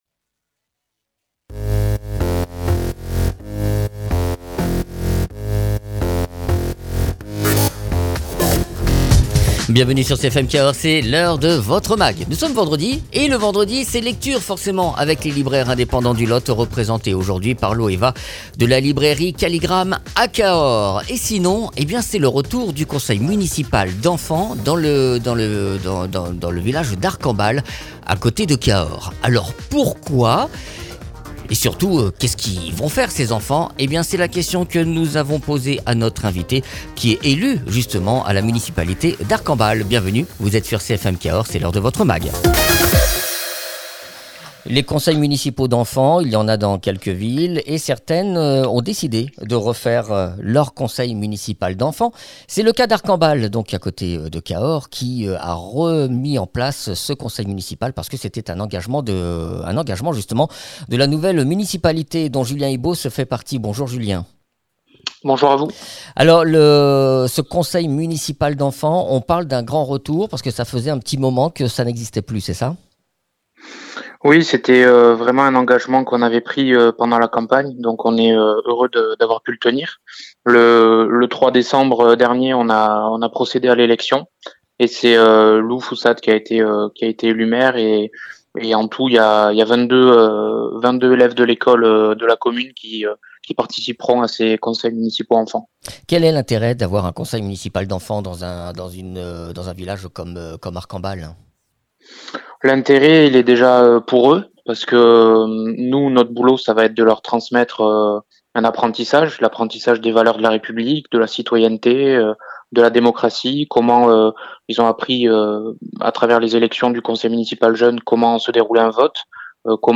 Invité(s) : Julien Ibos, conseiller municipal d’Arcambal.